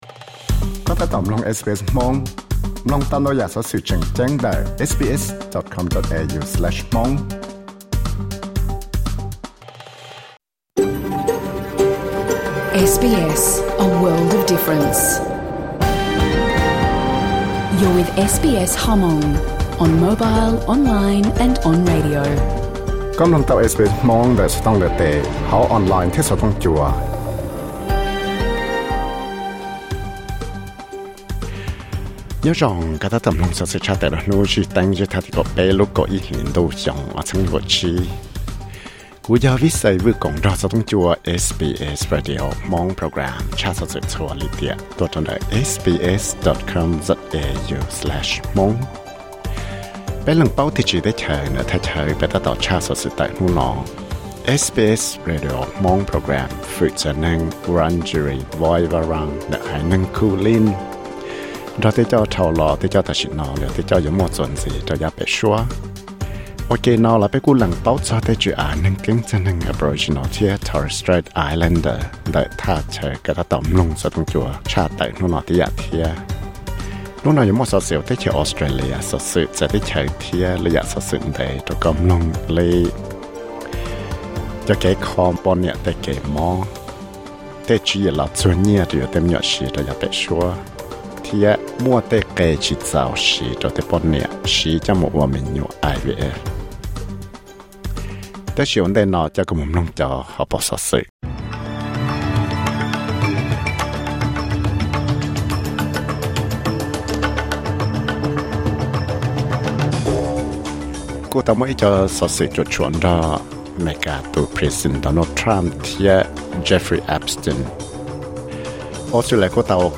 Thursday bulletin: Liberals tsis siv nws tsab cai net zero policy